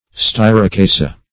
Definition of styracaceae.
styracaceae.mp3